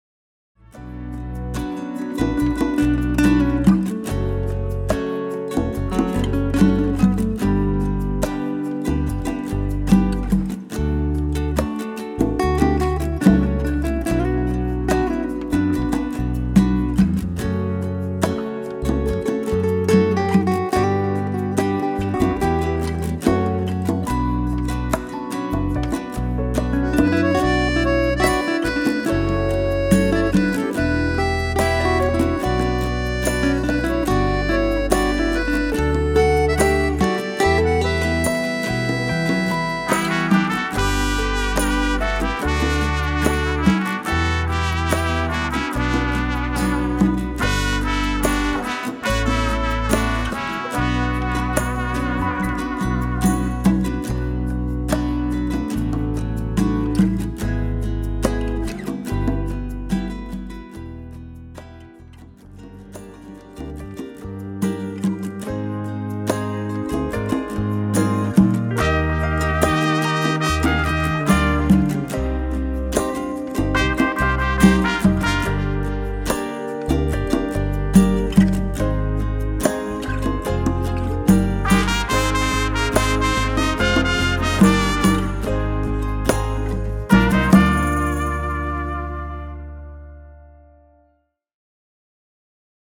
פלייבק